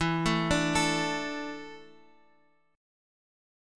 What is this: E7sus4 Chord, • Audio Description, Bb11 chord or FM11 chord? E7sus4 Chord